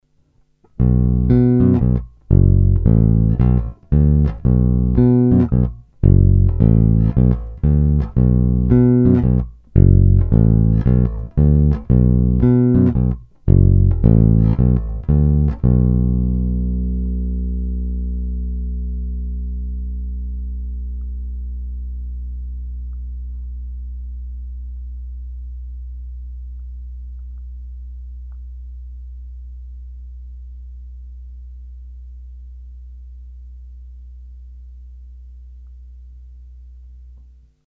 Naprosto typický Jazz Bass, ve zvuku hodně vnímám rezonanci samotného nástroje a desítky let jeho vyhrávání.
Není-li uvedeno jinak, následující nahrávky jsou vyvedeny rovnou do zvukovky, s plně otevřenou tónovou clonou a jen normalizovány, jinak ponechány bez úprav.
Krkový snímač